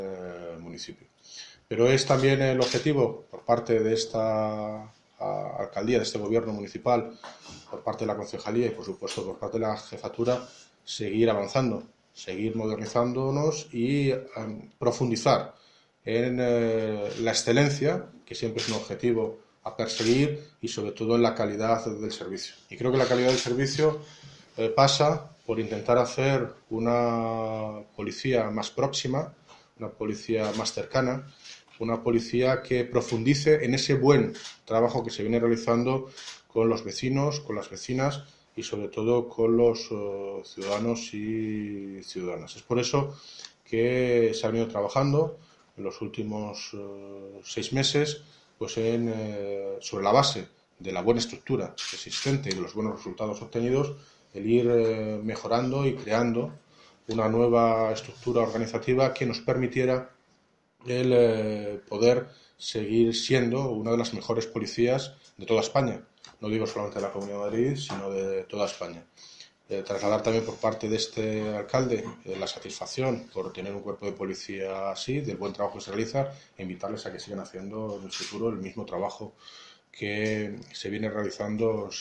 Audio - David Lucas (Alcalde de Móstoles) Sobre Presentacion nueva Organizativa Policia